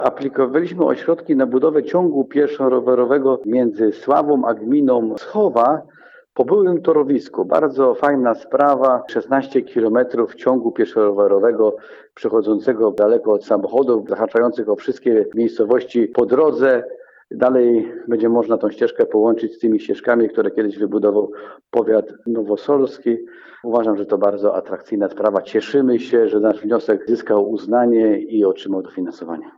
– Trasa będzie biegła po nieczynnej już linii kolejowej – powiedział starosta Andrzej Bielawski: